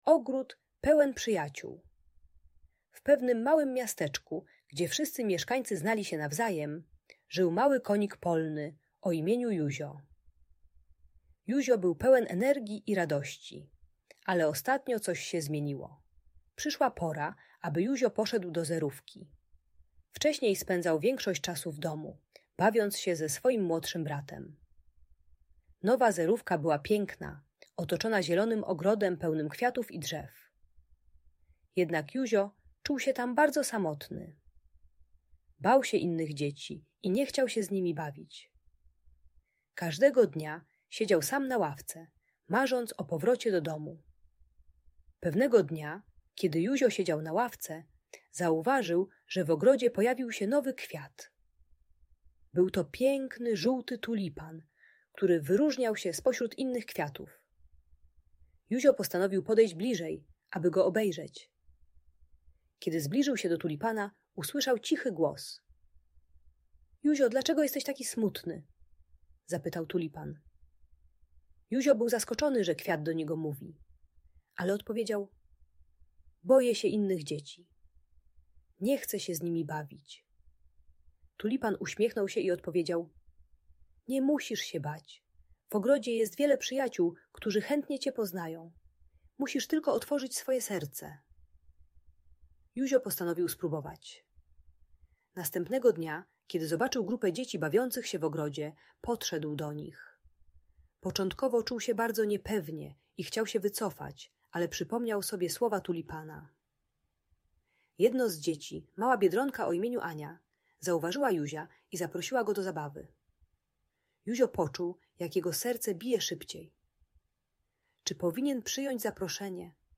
Ogród Pełen Przyjaciół - Lęk wycofanie | Audiobajka